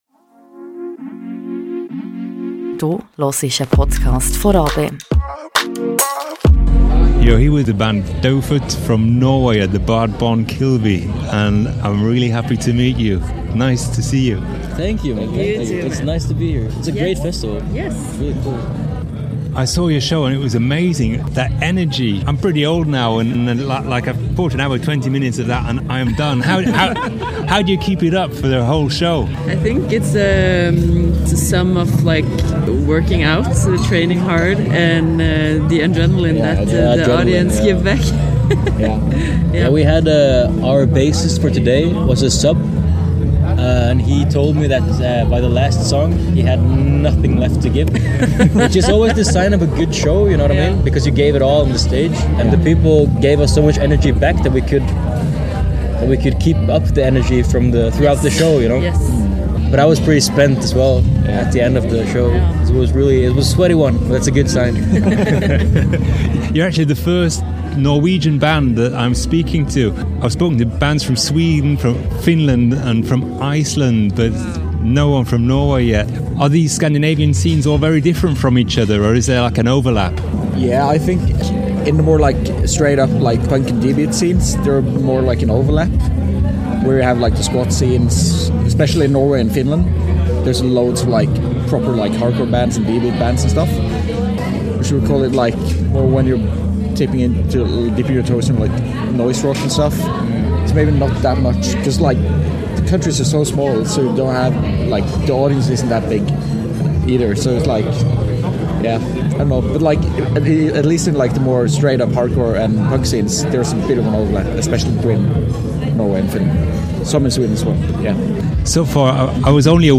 Amplifier-Interview with Daufødt